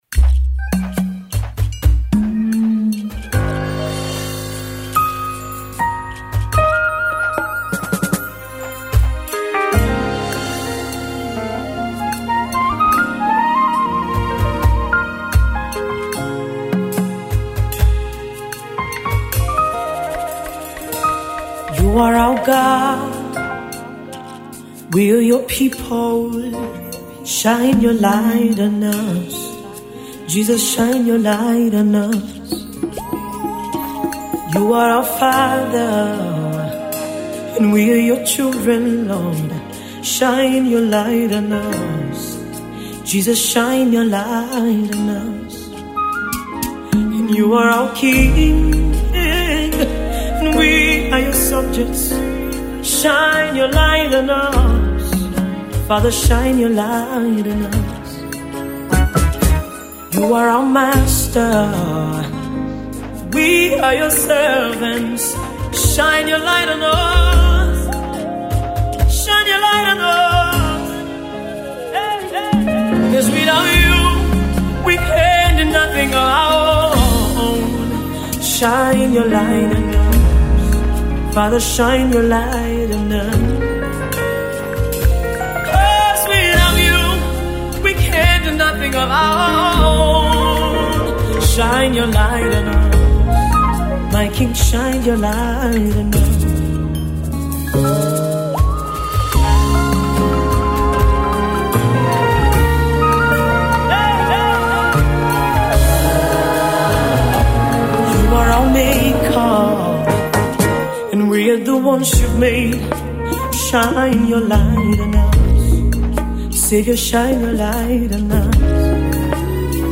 sonorous Afro Soul singer